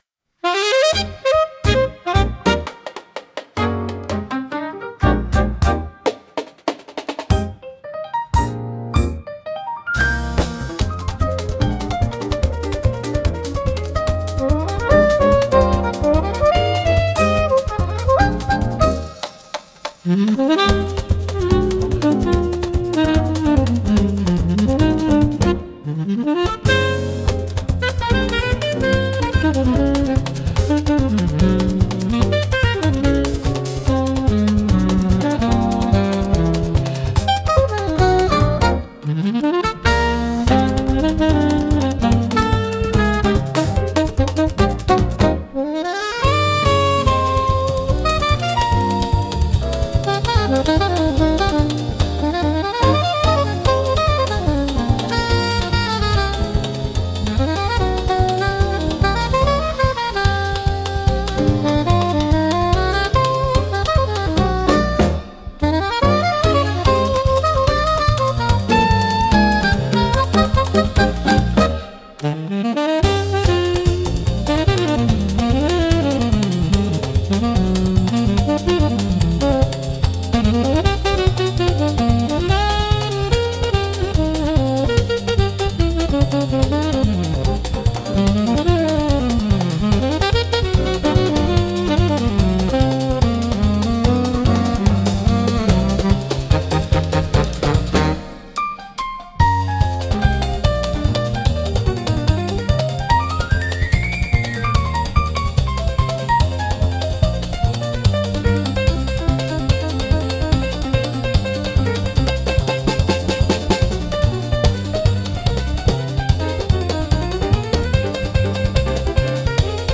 música e arranjo: IA) (Instrumental)